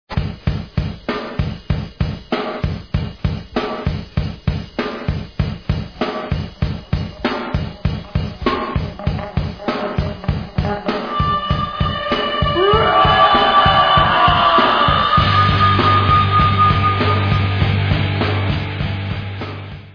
sledovat novinky v oddělení Rock/Hardcore